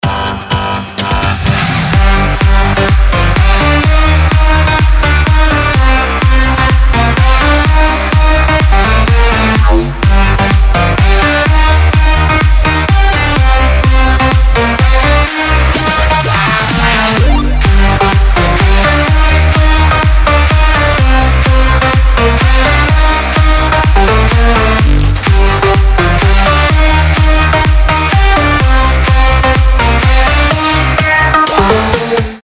• Качество: 320, Stereo
громкие
без слов
club
electro house